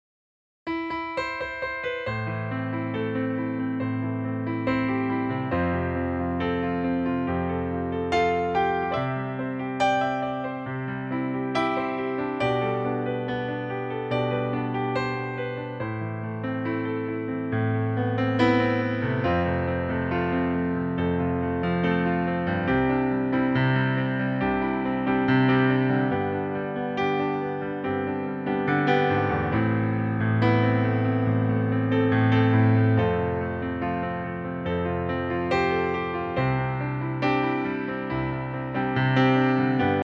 Demo in A-moll
A-moll